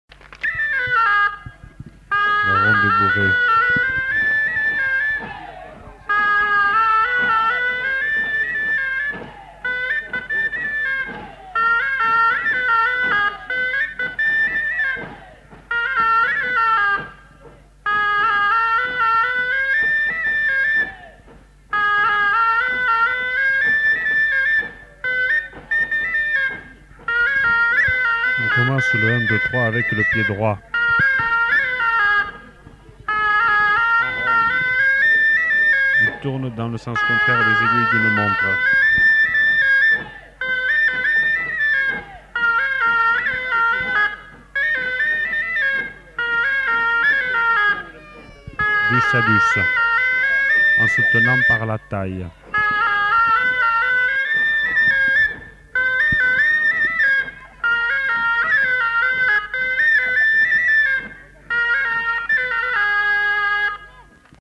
Lieu : Castillon-en-Couserans
Genre : morceau instrumental
Instrument de musique : aboès
Danse : bourrée d'Ariège